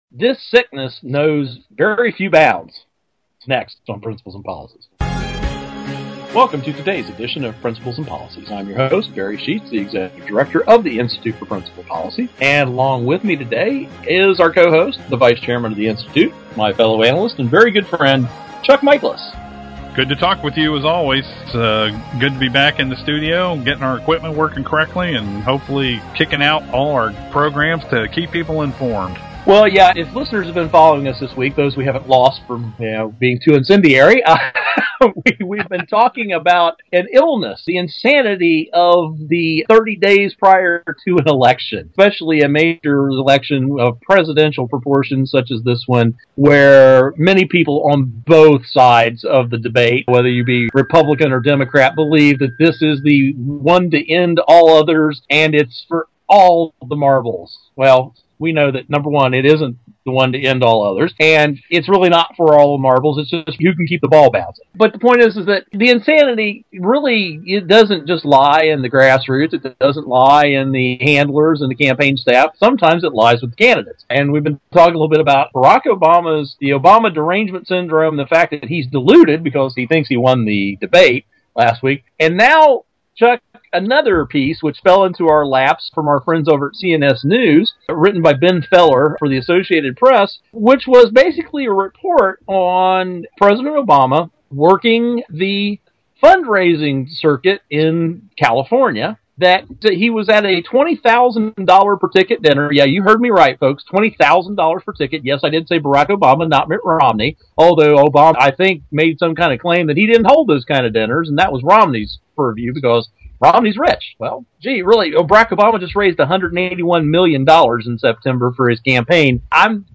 Our Principles and Policies radio show for Friday October 12, 2012.